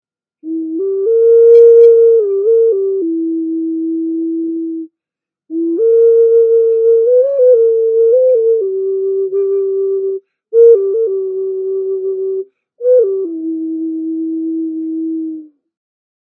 Ancient Musical instrument Ocarina clay flute
The Ocarina, hand tuned to produce the most awakening sound.
This musical instrument was made to resemble a armadillo or opossum by the artist. A recording of the sound of this particular ocarina is in the top description, just click on the play icon to hear the sound.